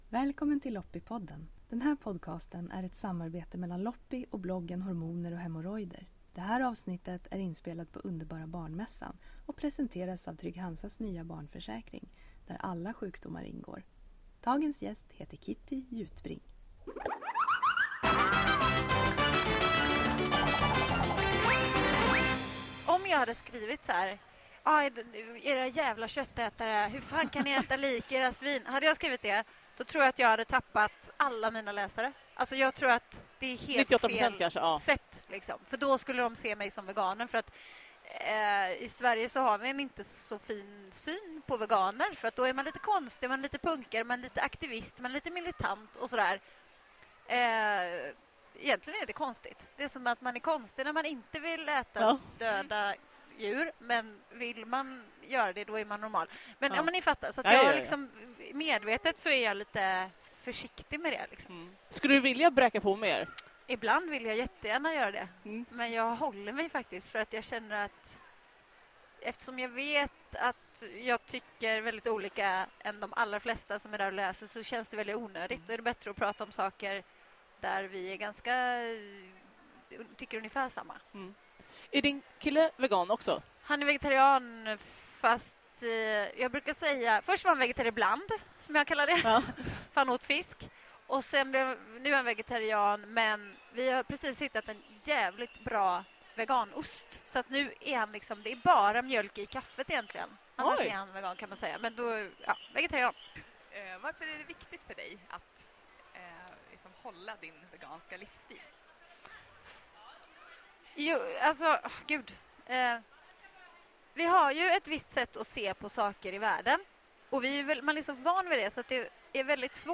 I varje avsnitt intervjuas en gäst som avslöjar sina erfarenheter av småbarnslivet, vardagskaoset och hur man överlever sina första år som förälder.